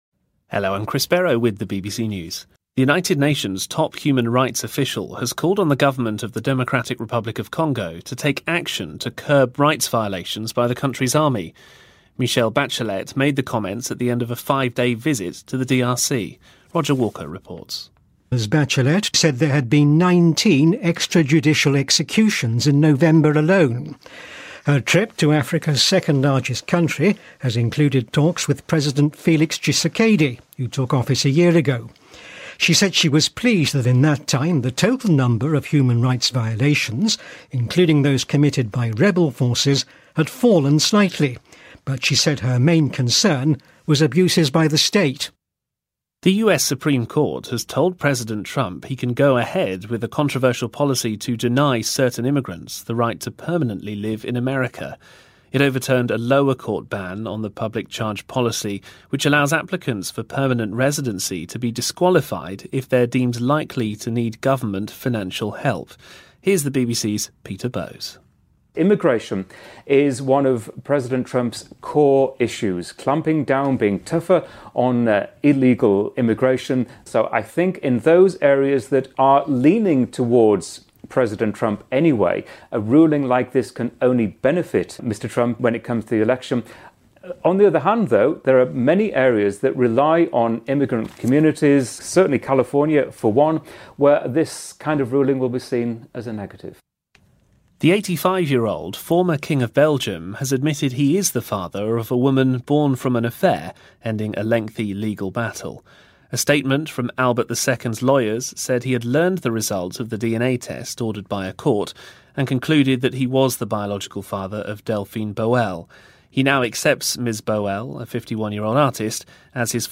英音听力讲解:联合国关注刚果军队侵犯人权行为